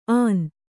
♪ ān